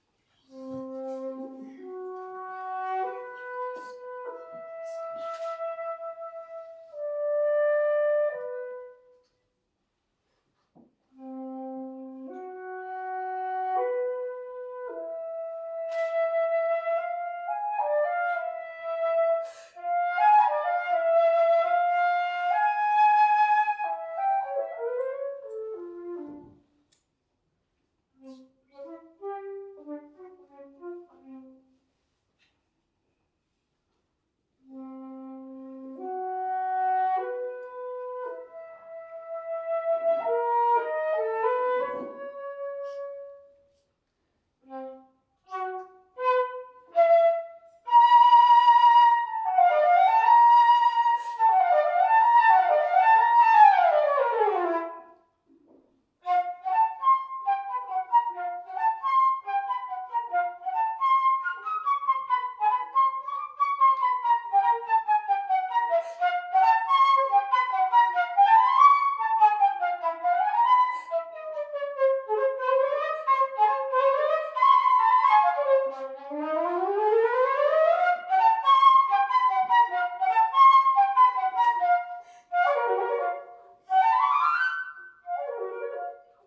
Solo
Extrait lors de la visite contée & concert, Musée Vulliod Saint-Germain, Pézenas, juillet 2023